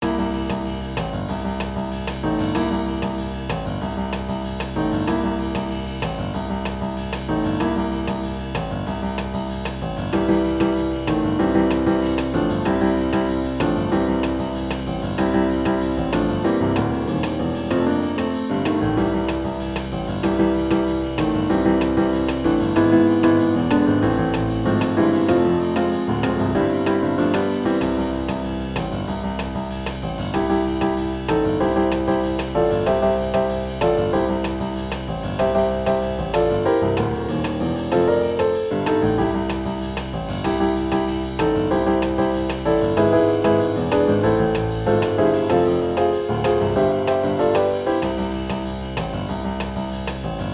generic jazz piano song
Upbeat Jazz song incoming.
jazz piano drums upbeat garageband